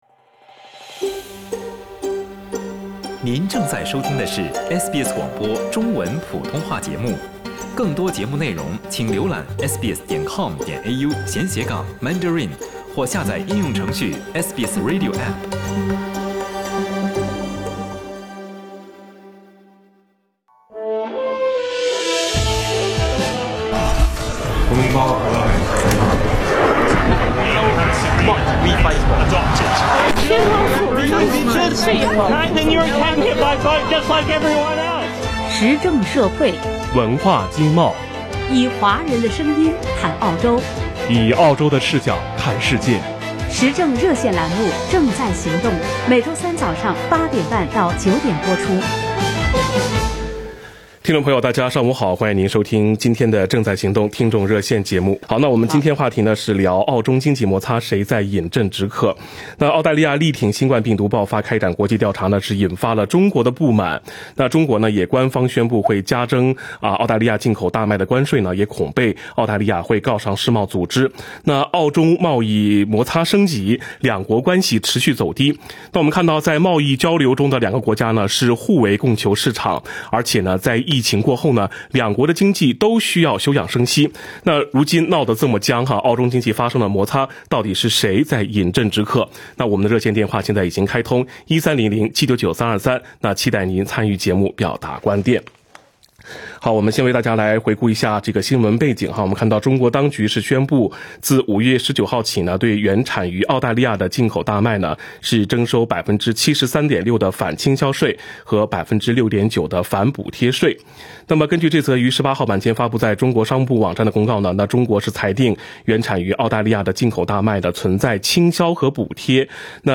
参与热线讨论的多数听众认为，澳大利亚处理澳中关系的做法有欠妥当，更有人特别指出澳大利亚因此受到经济制裁“理所应当”。
听众热线讨论：澳中经济摩擦，谁在饮鸩止渴？